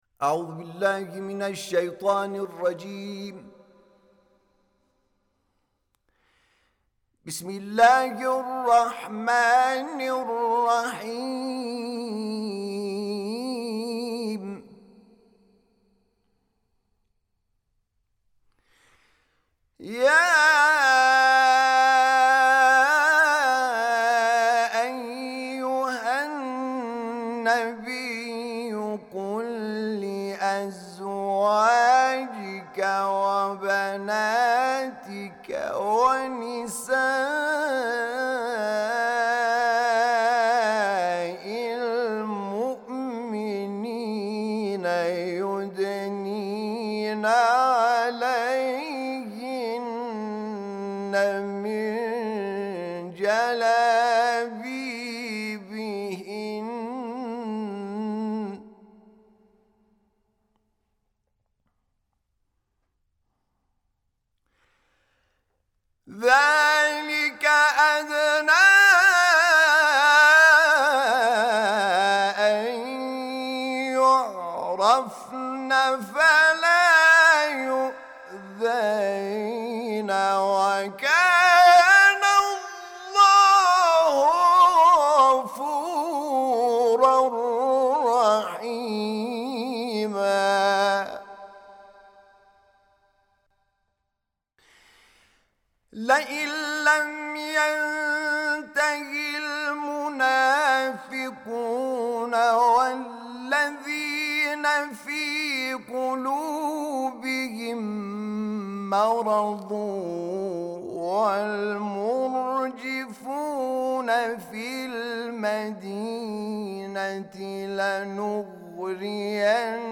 تلاوت آیه ۵۹ سوره مبارکه احزاب توسط حامد شاکرنژاد